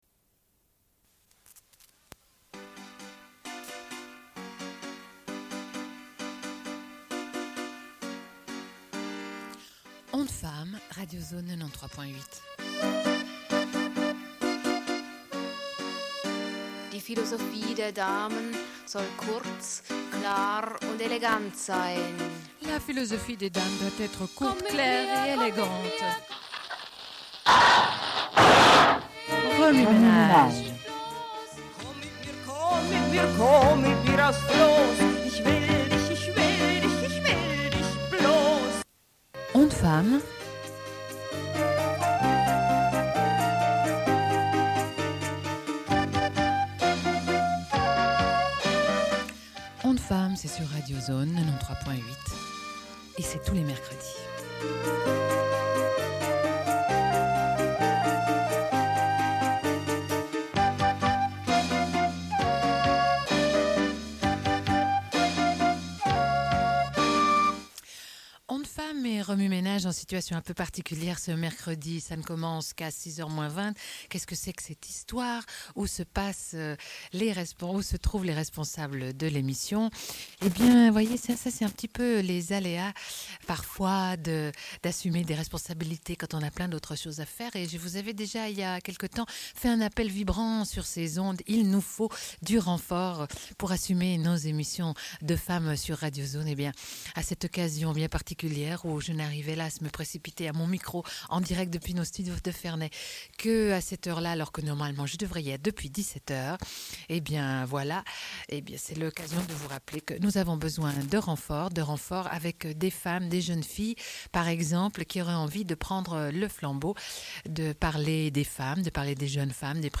Une cassette audio, face B
Radio